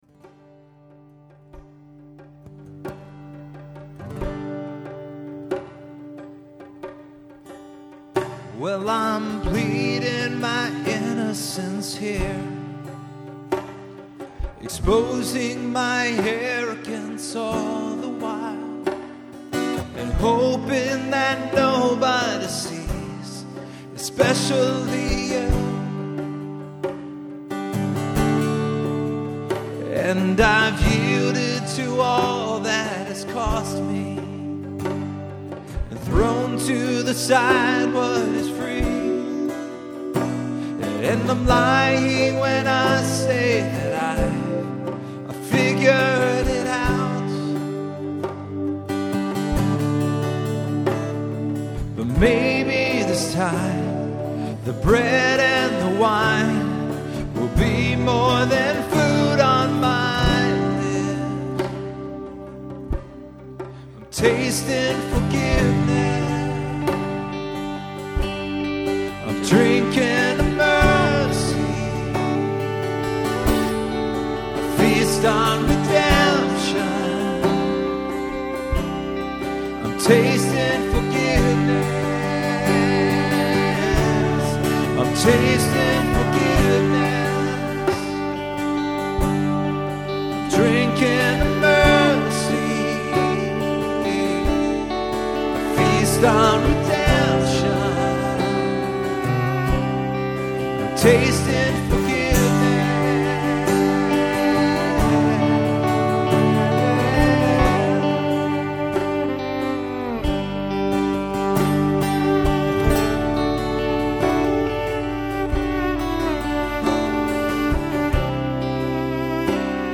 Performed live at Terra Nova - Troy on 1/11/09.